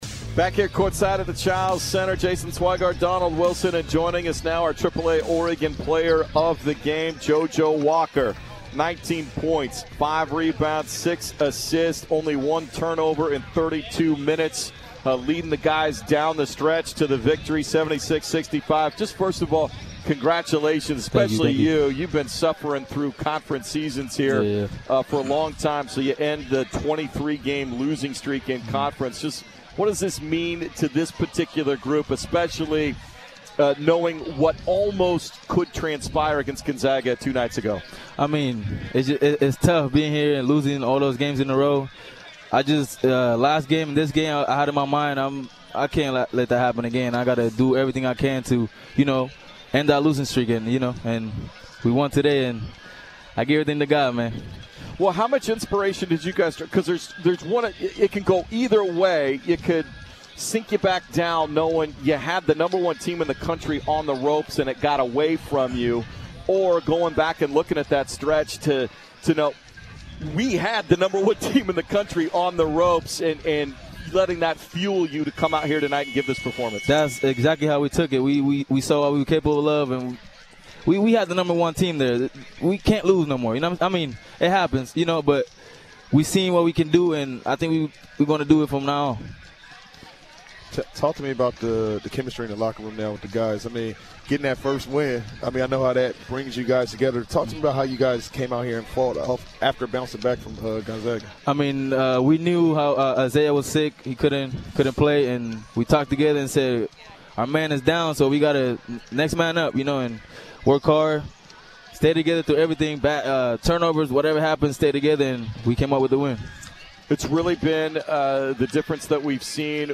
Men's Hoops Post-Game Interviews vs. San Francisco